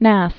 (năs)